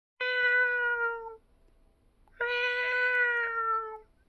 meowing like a pro.
meow.mp3